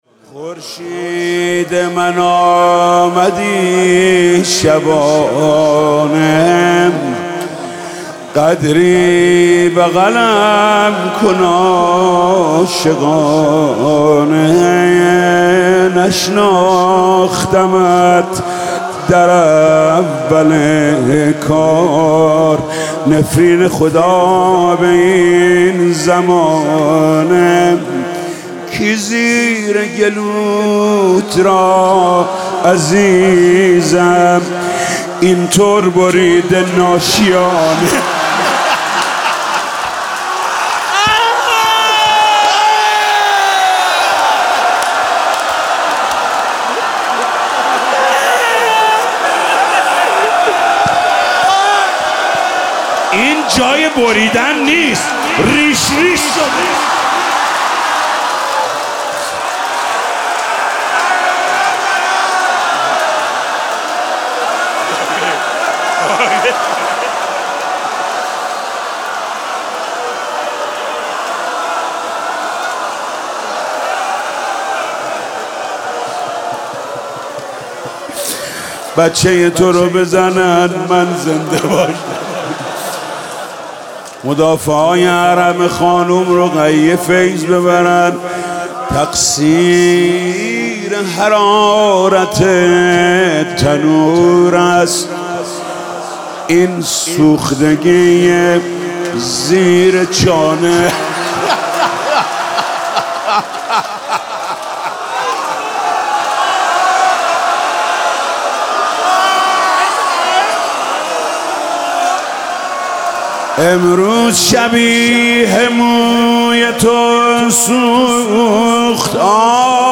برگزاری مراسم محرم حسینی 1401